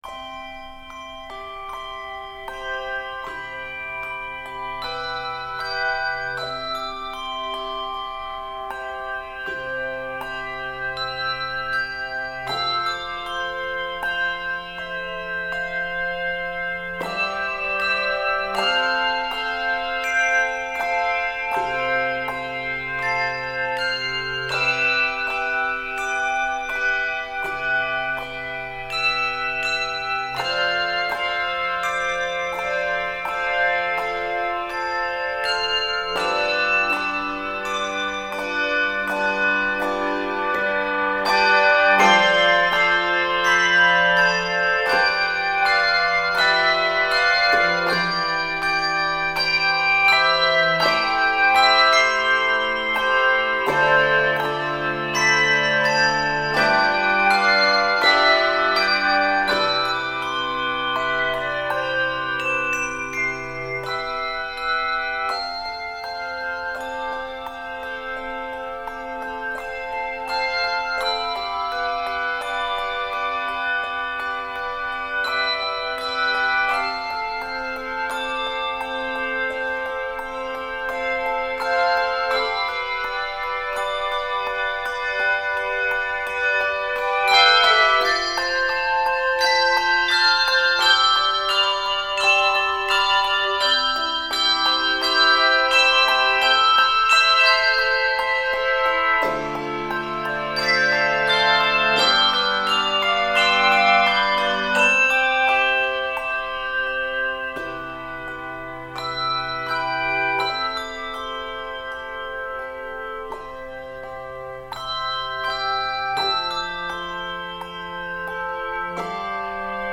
Various Octaves: 3-5 Level